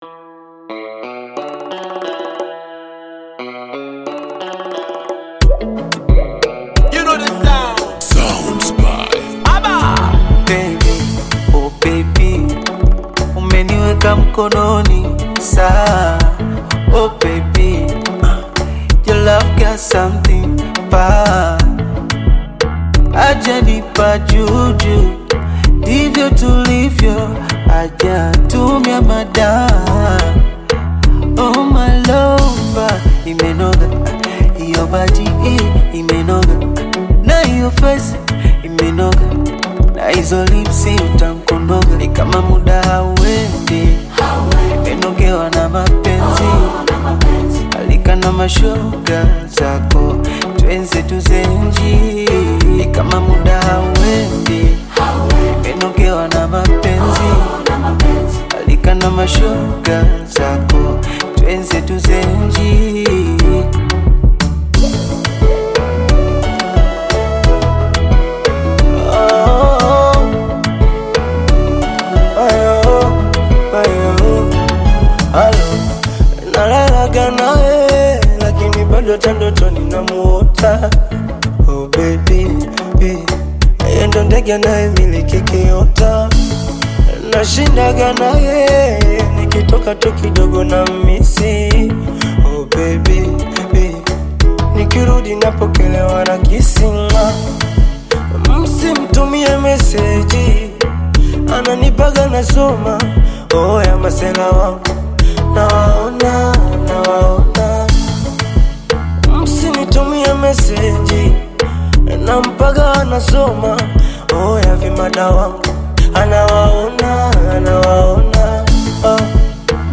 Genre: Bongo Flava